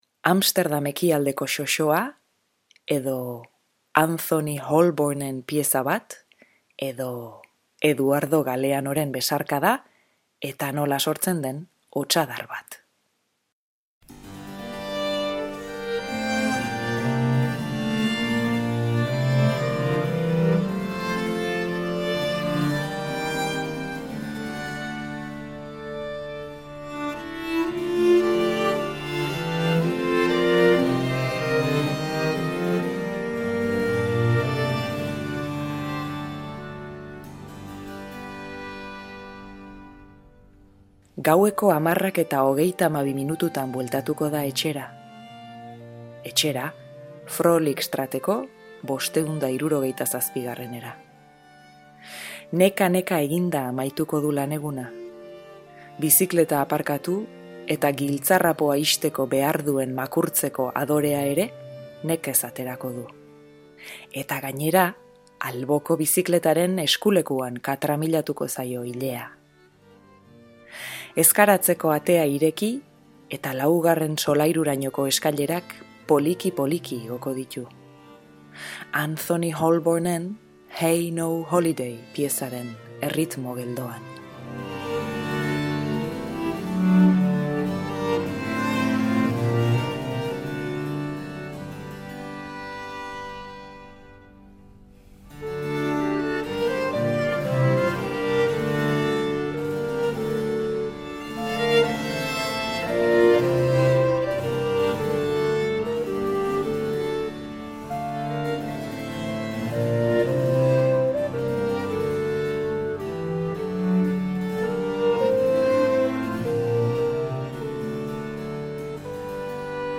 Audioa: Amsterdamgo xoxotik, Anthony Holborneren Heigh ho Holidayra, Eduardo Galeanoren Besarkaden liburuan barrena. Capriccio Stravagantek jotako pieza entzunez.